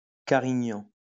Carignan (French pronunciation: [kaʁiɲɑ̃]